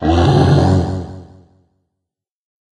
bear_spawn_01.ogg